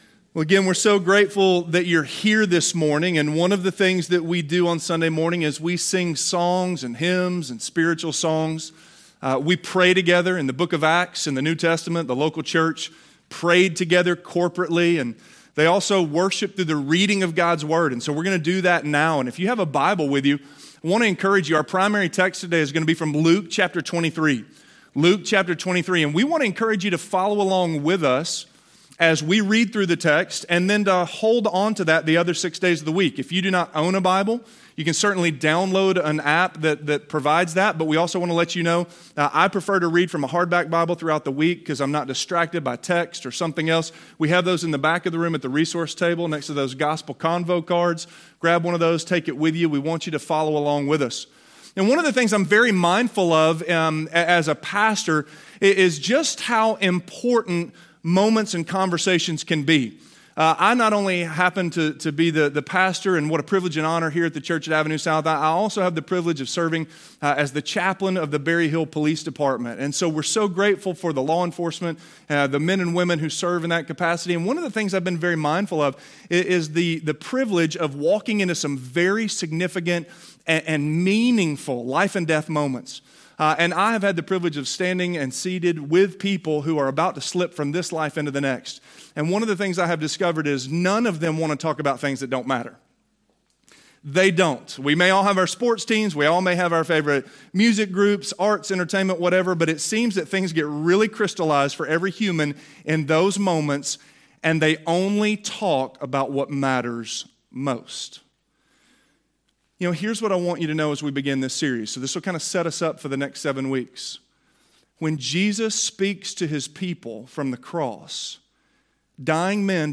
Father, Forgive Them - Sermon - Avenue South